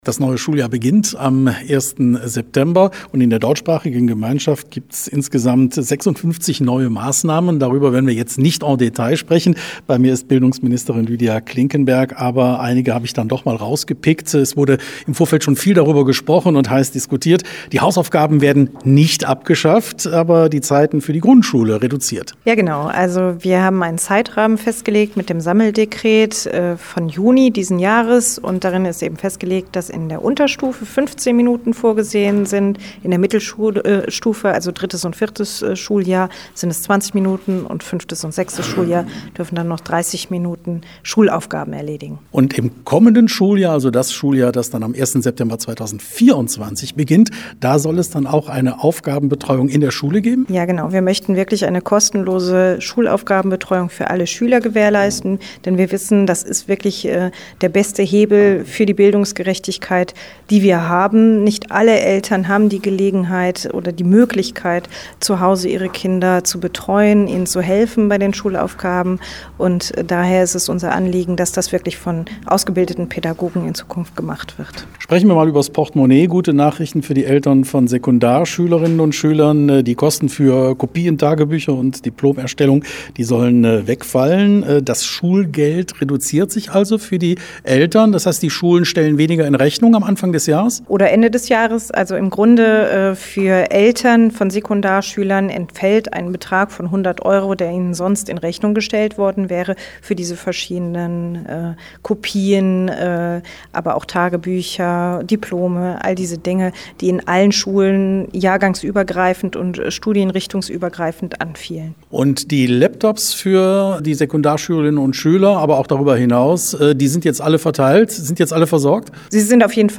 sprach mit Bildungsministerin Lydia Klinkenberg über die wichtigsten Punkte.